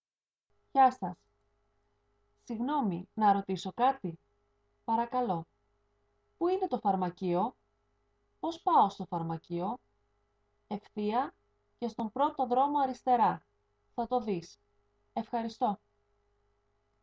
Dialog A